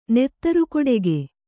♪ nettaru kodege